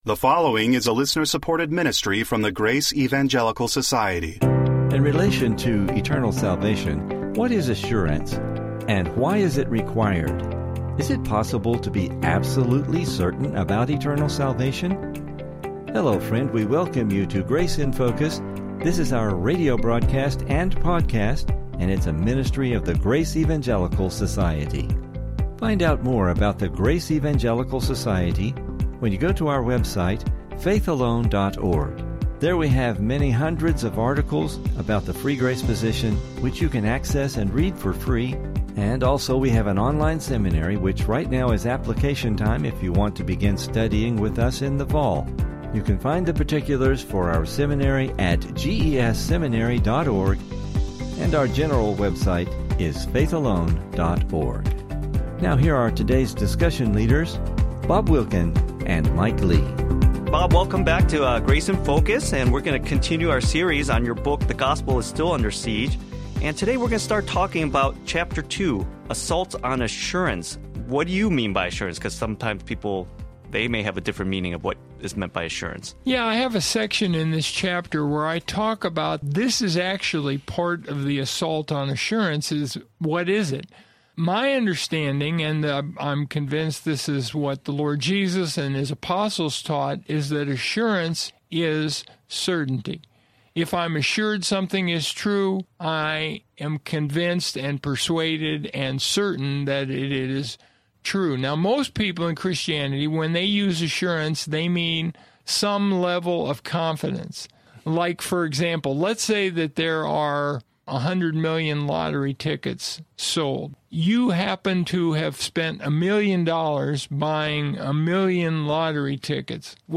Please listen for an interesting Biblical discussion regarding this subject!